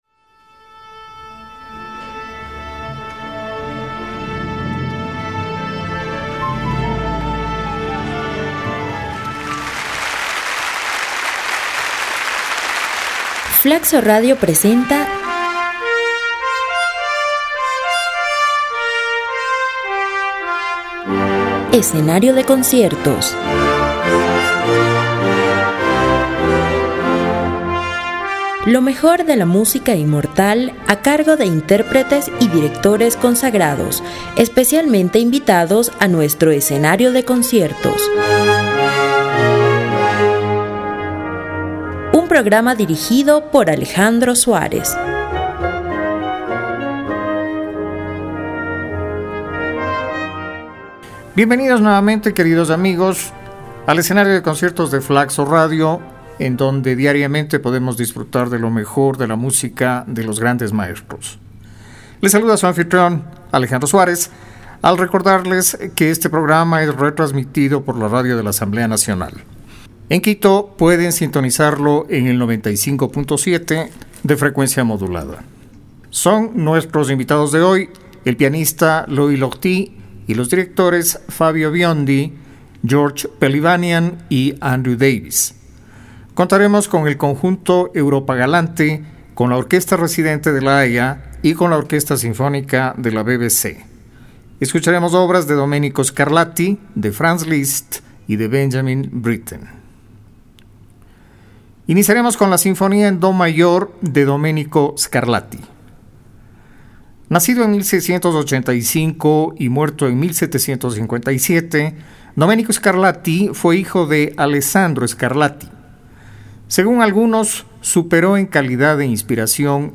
Escucharemos también una obra casi olvidada de Franz Liszt, el Concierto patético para piano y orquesta, de origen algo intrincado y al que la posteridad ha prestado poca atenció